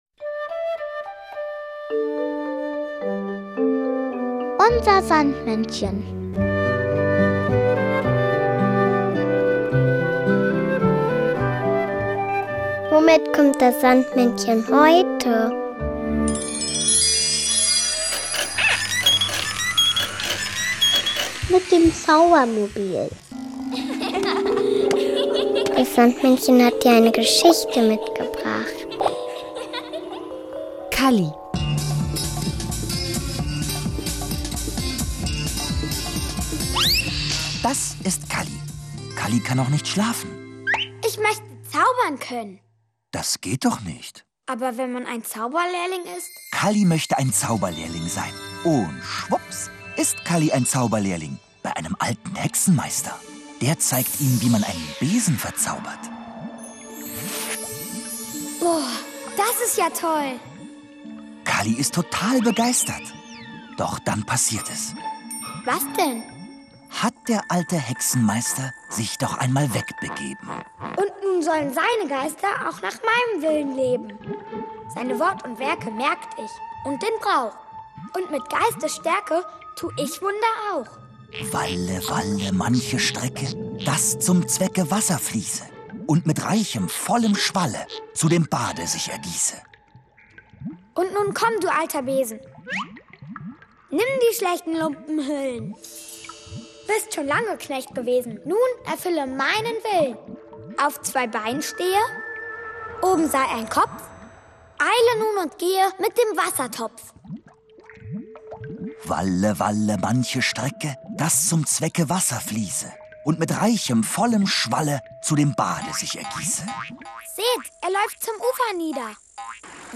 Neben bekannten Figuren wie den Erdmännchen Jan und Henry, Kalli oder Pittiplatsch bietet der Podcast großartige Märchen und lustige Geschichten. Und das Beste: Man hört, mit welchem Fahrzeug das Sandmännchen heute vorbeikommt! UNSER SANDMÄNNCHEN hat aber nicht nur zauberhafte Hörspiele dabei, sondern auch noch ein passendes Lied und den berühmten Traumsand.